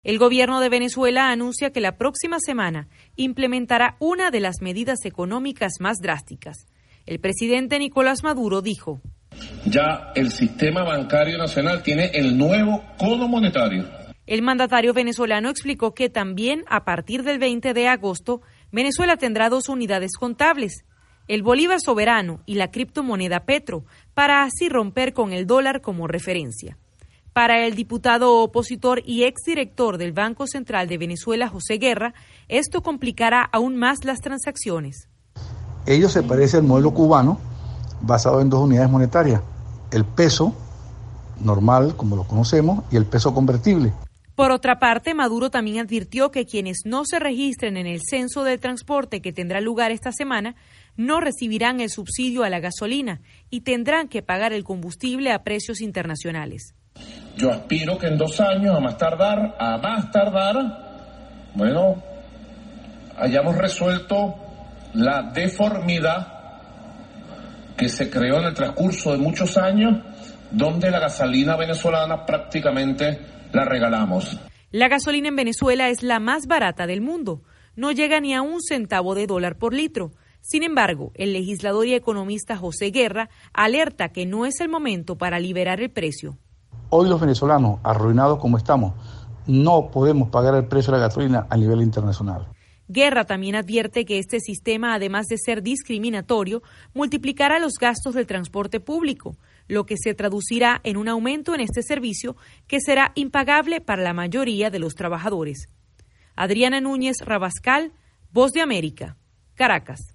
VOA: Informe de Venezuela